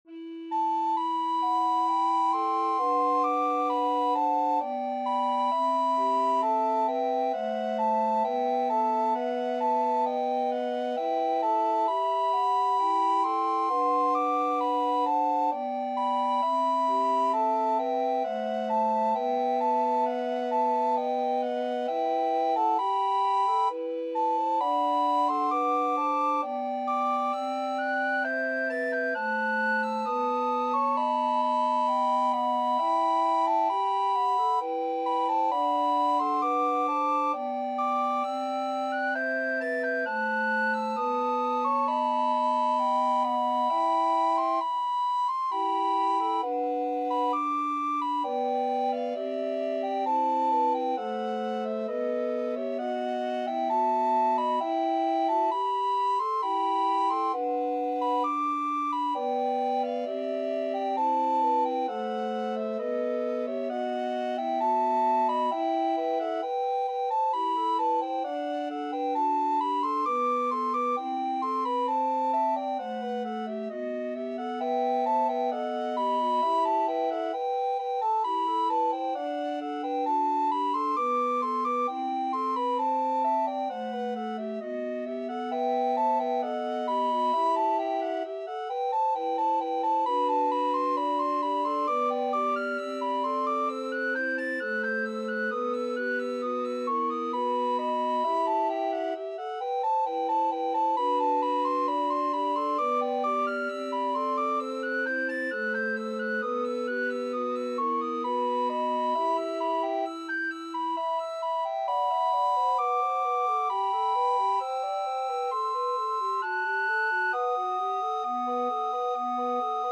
Soprano RecorderAlto RecorderTenor RecorderBass Recorder
3/4 (View more 3/4 Music)
Classical (View more Classical Recorder Quartet Music)